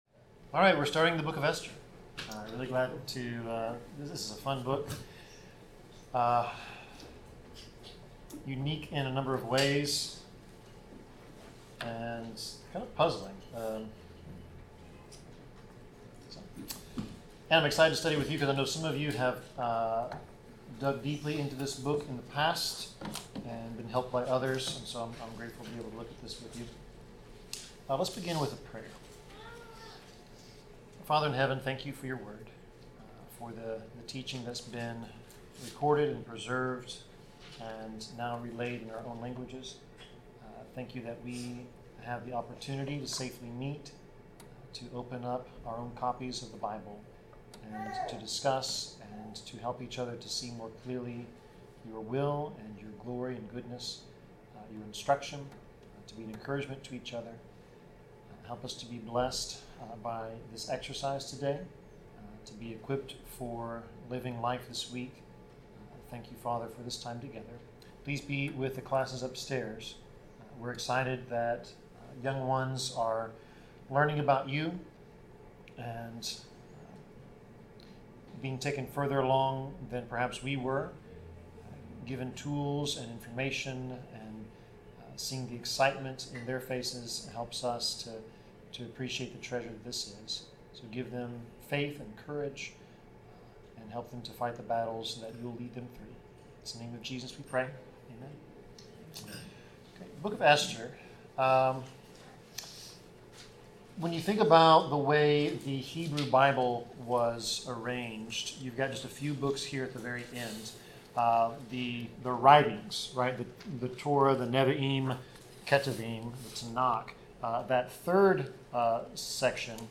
Bible class: Esther 1-2
Service Type: Bible Class